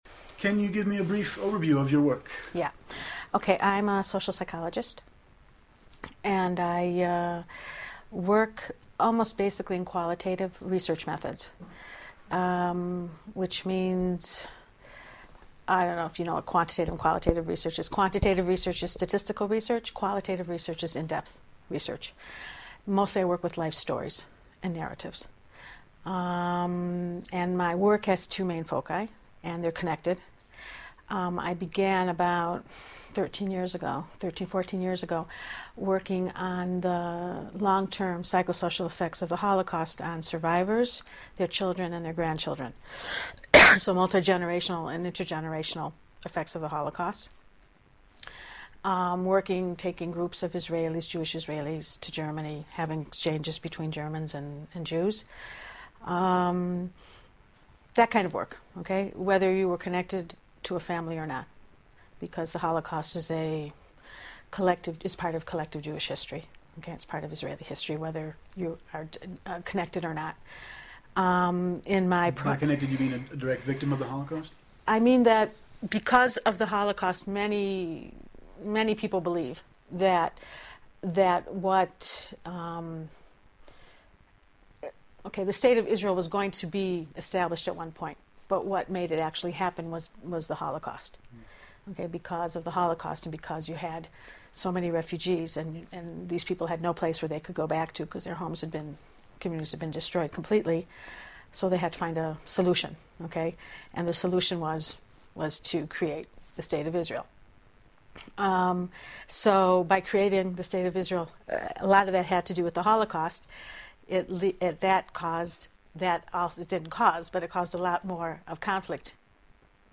Listen/Read Selected Interview Segments on the Following Topics